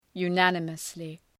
Προφορά
{ju:’nænəməslı}